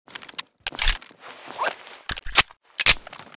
colt_reload_empty.ogg